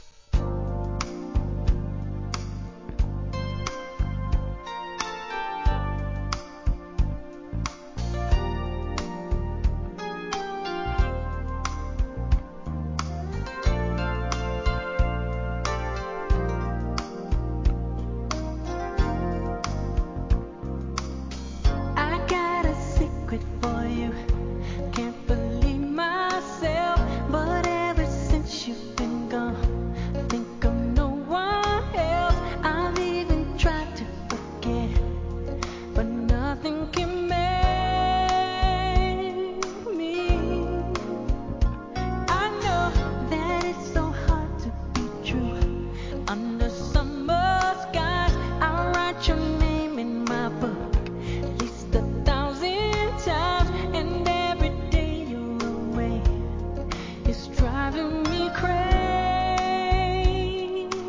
¥ 990 税込 関連カテゴリ SOUL/FUNK/etc...